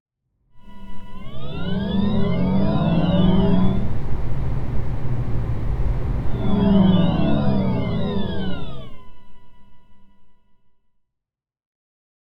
El sonido del VW ID.3
Por este motivo, todos deben emplear un Sistema de Alerta Acústica de Vehículo (AVAS, por sus siglas en inglés) para generar un sonido de conducción artificial.
Como establece la norma, el AVAS del ID.3 resulta audible dentro y fuera del vehículo a velocidades de hasta 30 km/h. Dentro del vehículo, siempre pueden oírse las distintas etapas del sonido de conducción en función de la velocidad y de la posición del pedal de aceleración.
sonido-de-conduccion-id-3.wav